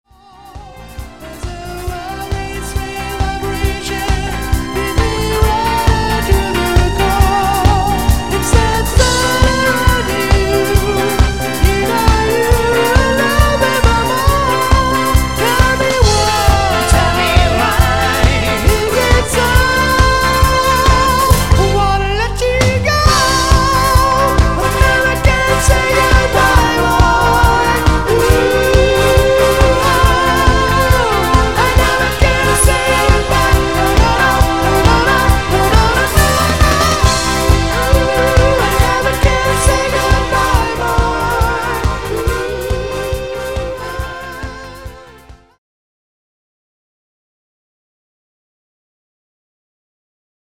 Gesang
Keyboard
Bass
Gitarre
Drums